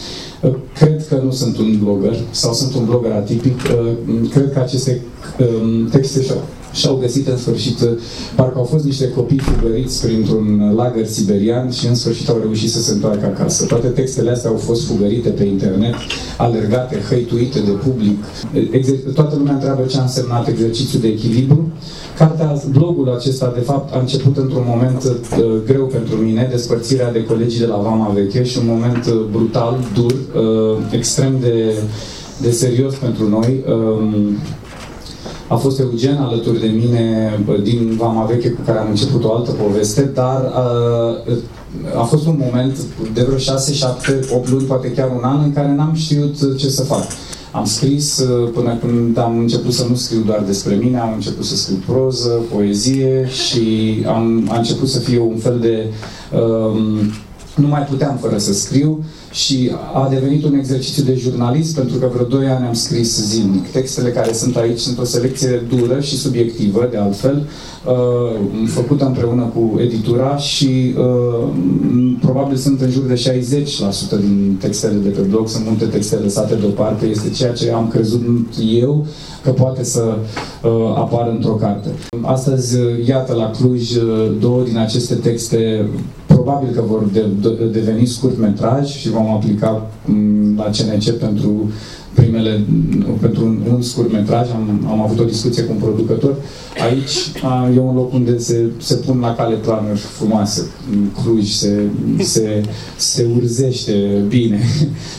Tudor Chirilă a lansat aseară prima sa carte „Exerciṭii de echilibru”, la Cluj.
Sala destinată prezentării a fost arhiplină, iar majoritatea persoanelor de faṭă a achiziționat cartea, unii chiar în dublu exemplar, pentru apropiaṭi. Atmosfera a fost una jovială, iar publicul era entuziasmat de întâlnirea cu solistul trupei „Vama”.